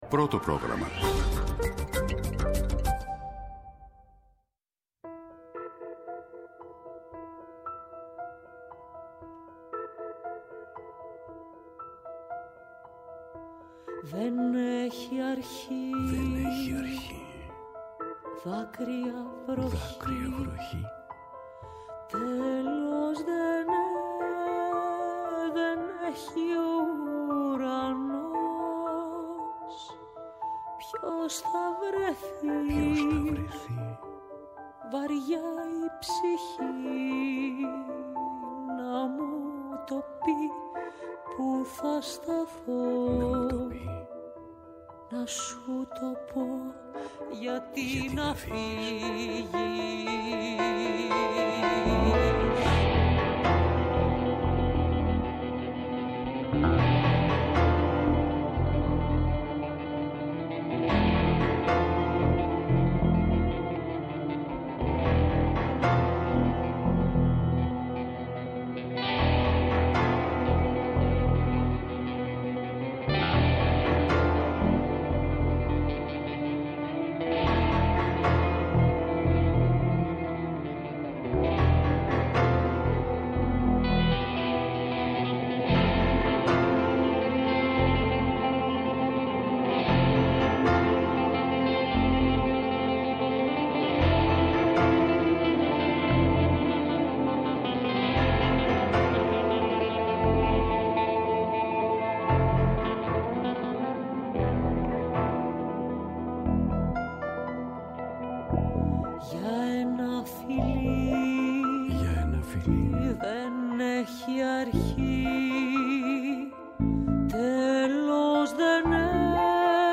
Καλεσμένος στο στούντιο της εκπομπής